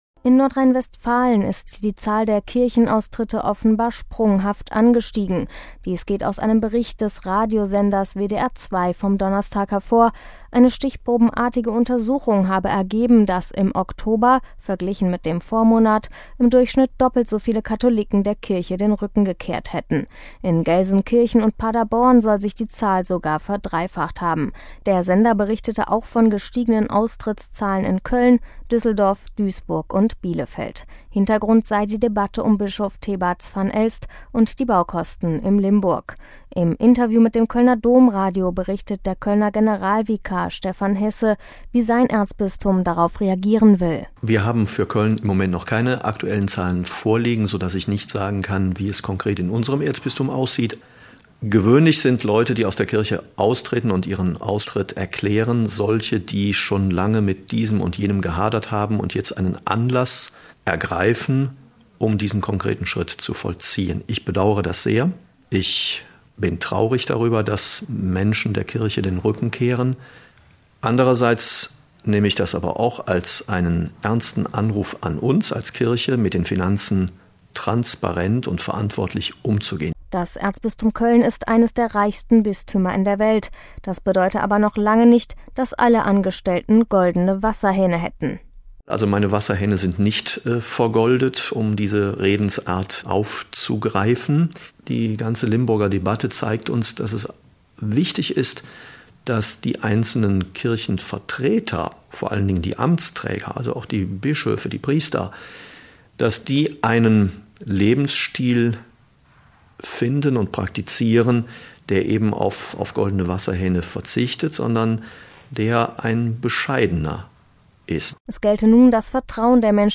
Im Interview mit dem Kölner Domradio berichtet der Kölner Generalvikar Stefan Heße, wie sein Erzbistum darauf reagieren will: